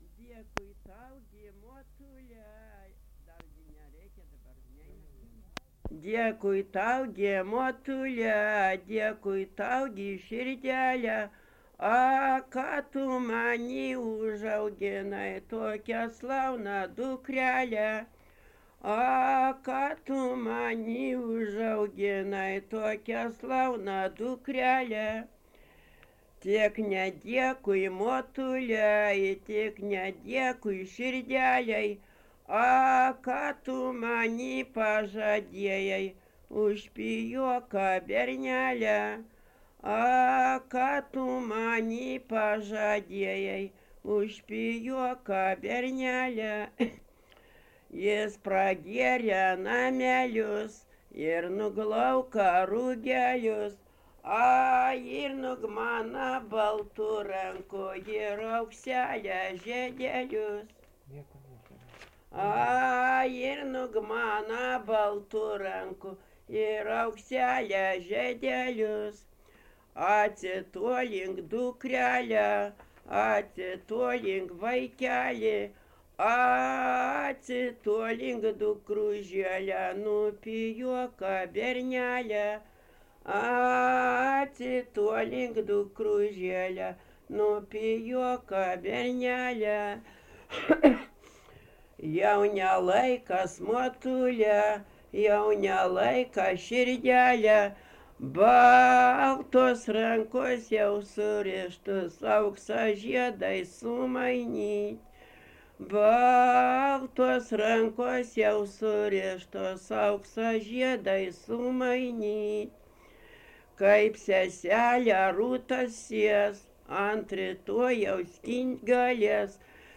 Dalykas, tema daina
Atlikimo pubūdis vokalinis
Pastabos Su komentaru